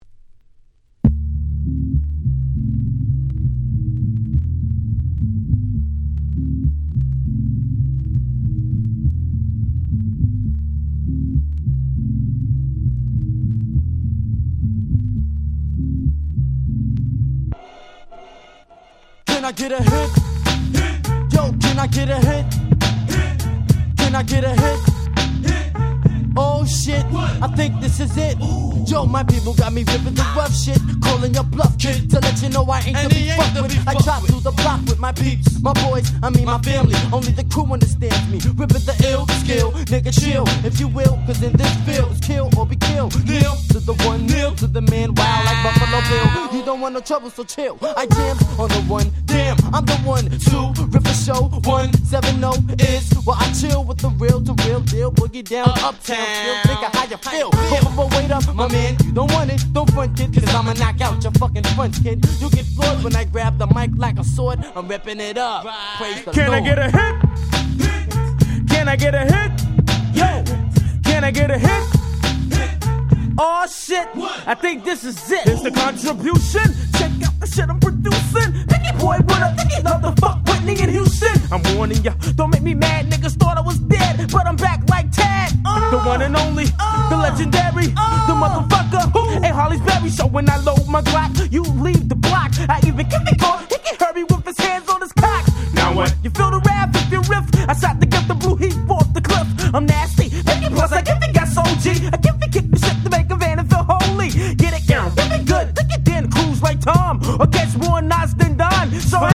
93' Very Nice Hip Hop !!
90's New School ニュースクール ジェイジー Boom Bap ブーンバップ